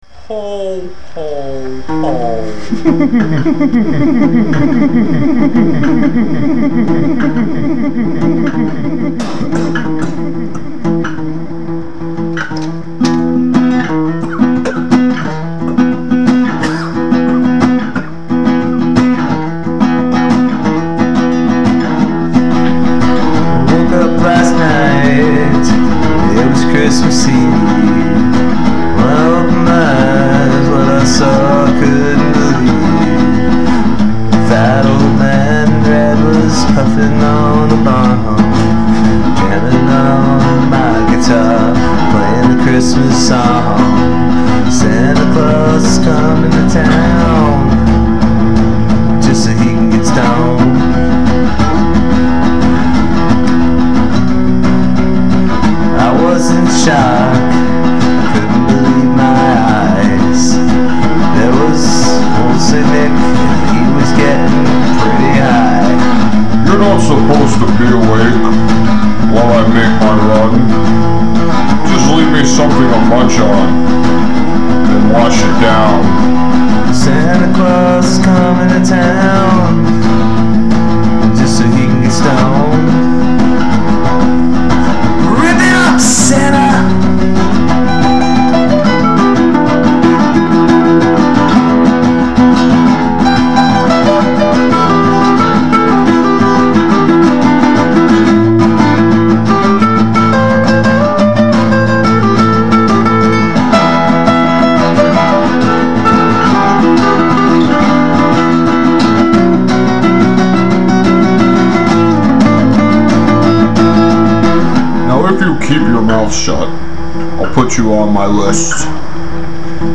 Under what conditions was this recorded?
Unplugged